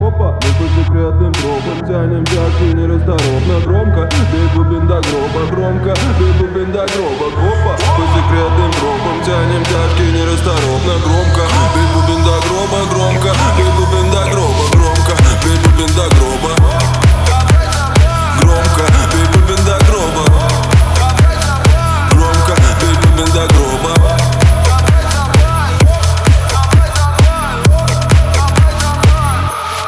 • Качество: 320, Stereo
мужской голос
громкие
русский рэп
энергичные
быстрые